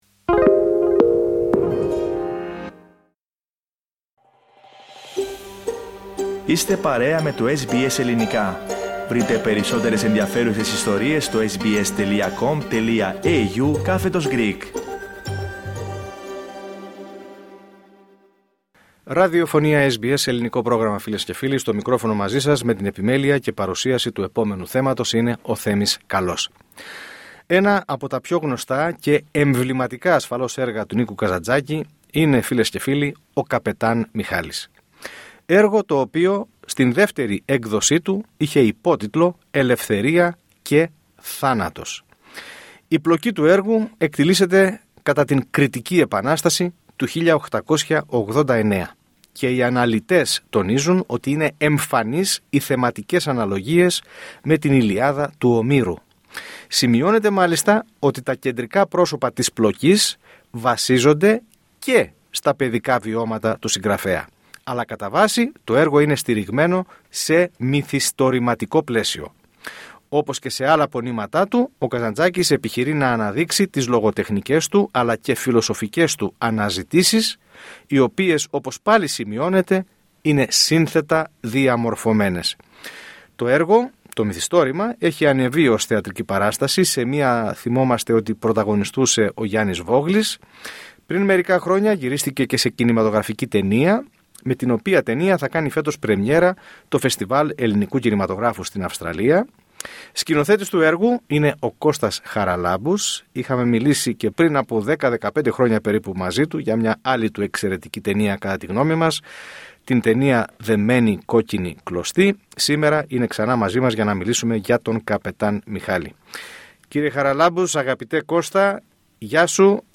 Στη συνέντευξη